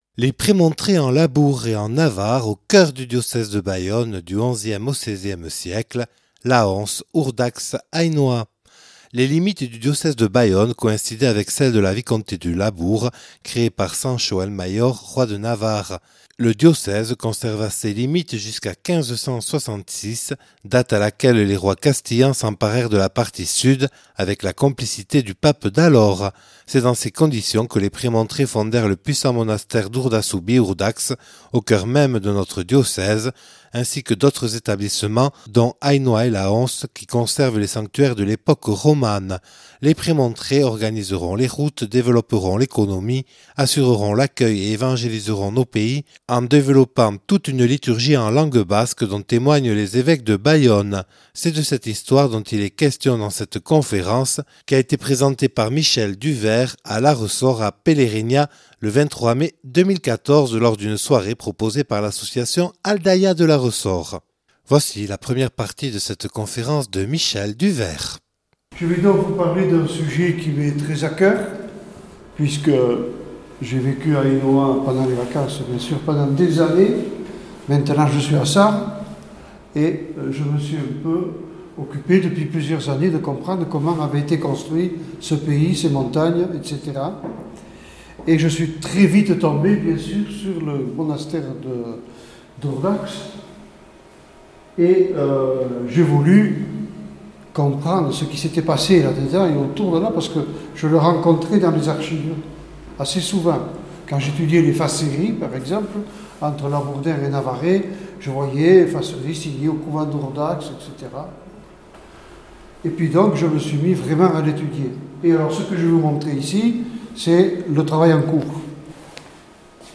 Conférence
(Enregistré le 23/05/2014 à Larressore lors d’une soirée proposée par l’association Aldaya).